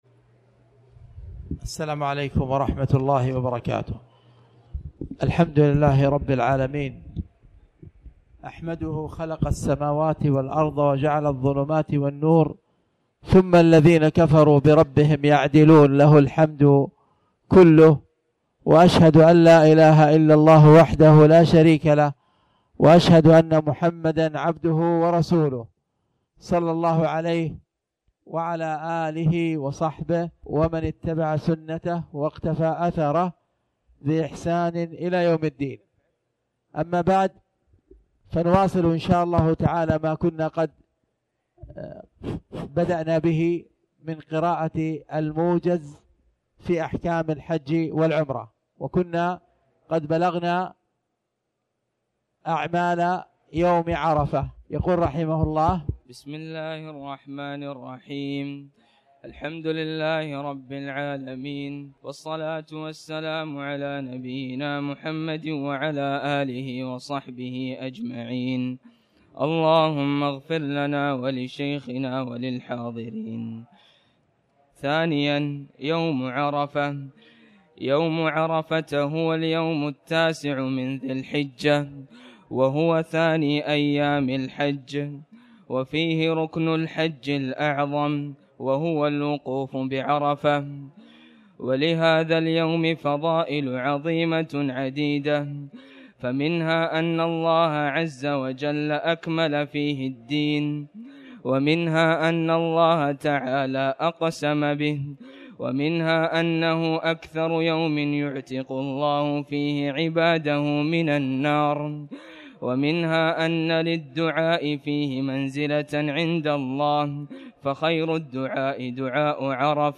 تاريخ النشر ١١ ذو القعدة ١٤٣٩ هـ المكان: المسجد الحرام الشيخ